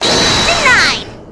objection.wav